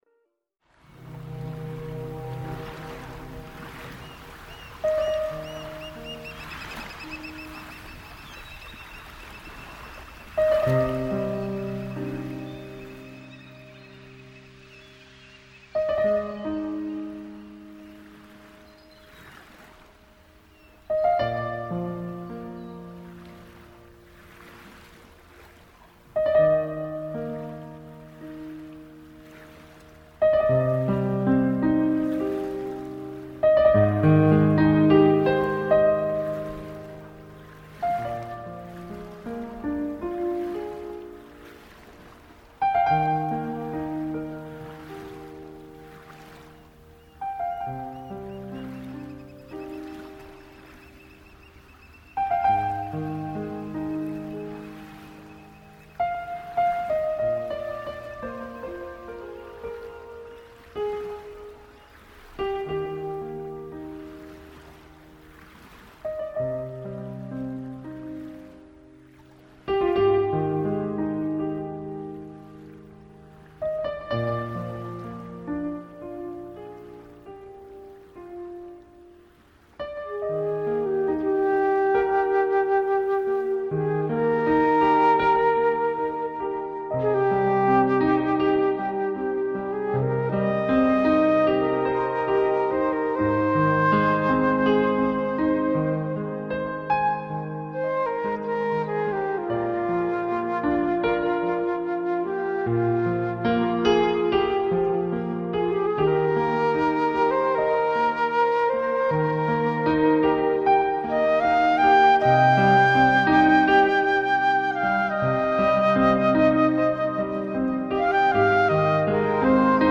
Música de relajación - DíaD
A continuación te presentamos la música de relajación que hemos utilizado como música de fondo en los ejercicios de autohipnosis.